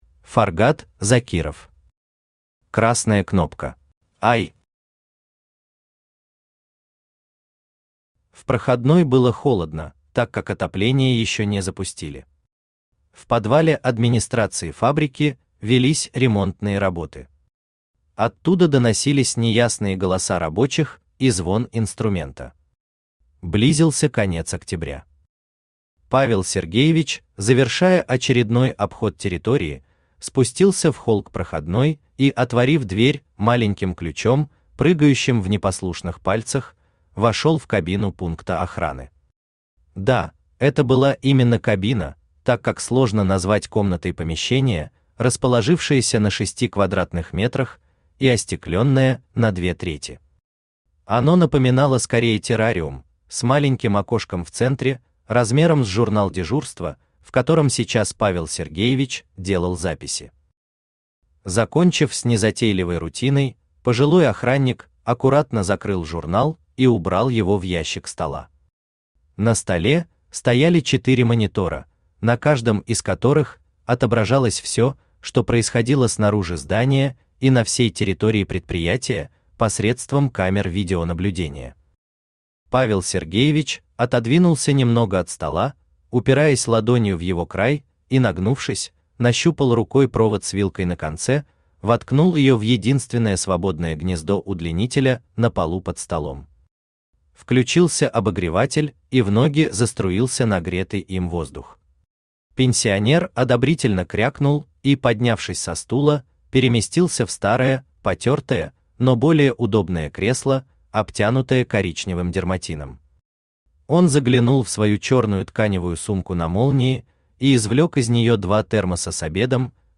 Aудиокнига Красная кнопка Автор Фаргат Закиров Читает аудиокнигу Авточтец ЛитРес.